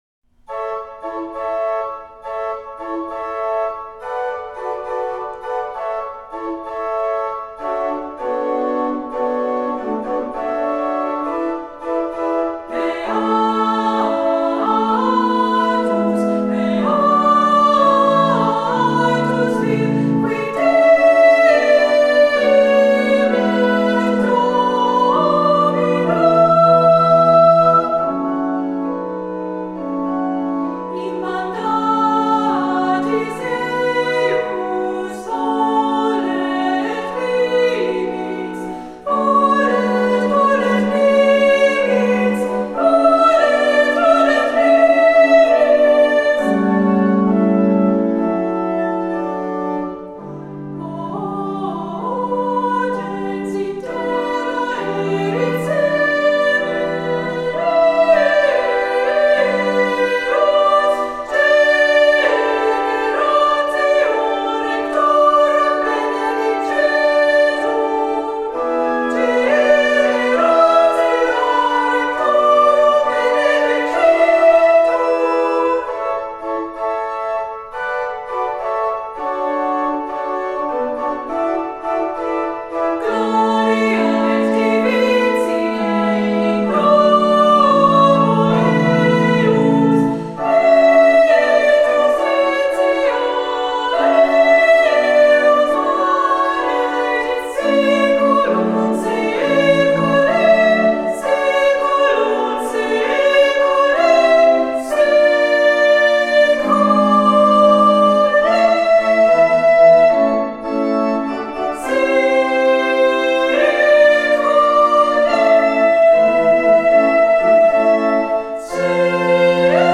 Voicing: Unison Choir